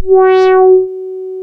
MG MOD.F#4 1.wav